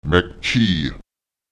Lautsprecher ma’qe- [mQÈkeù] Anti... (falsch: [maÈxE])